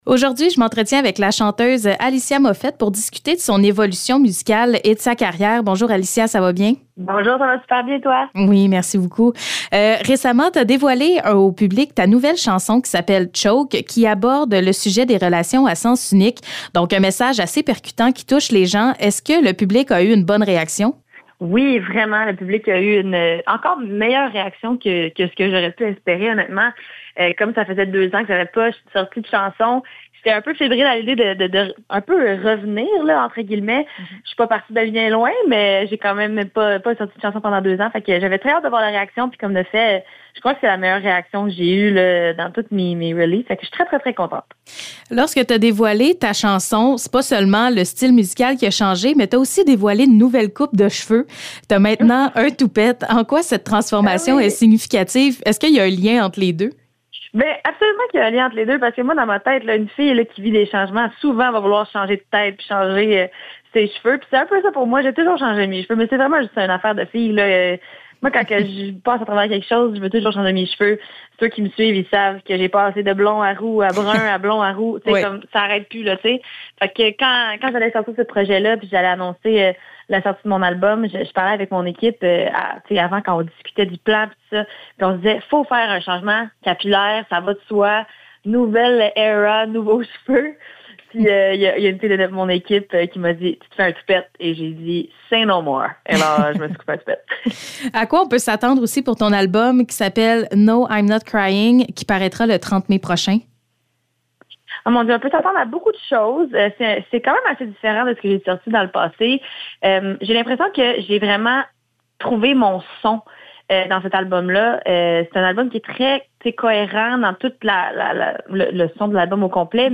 Entrevue Alicia Moffet
Entrevue avec Alicia Moffet concernant la sortie de sa nouvelle chanson Choke et de ses futurs projets en musique.